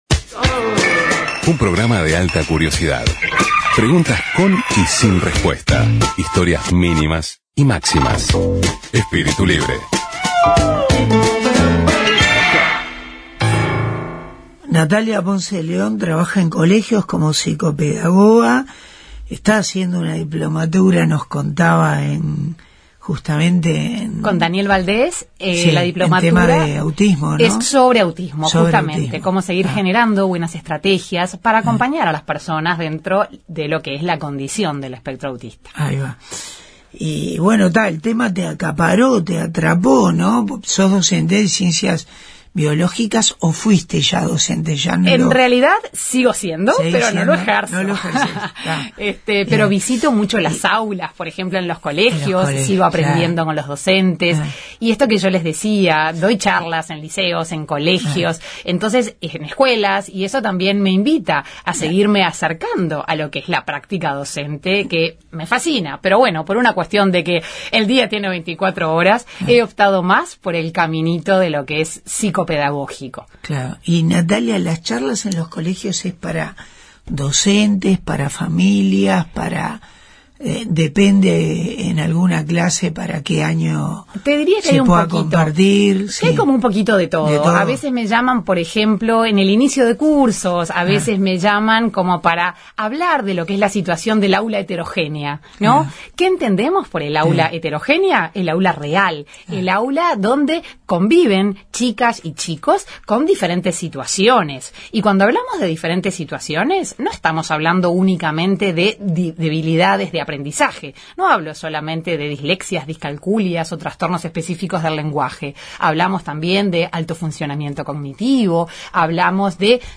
Entrevista a la docente postgraduada en Psicopedagogía Clínica